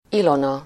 Ääntäminen
Ääntäminen US Tuntematon aksentti: IPA : /ˈhɛlən/ Haettu sana löytyi näillä lähdekielillä: englanti Käännös Ääninäyte Erisnimet 1.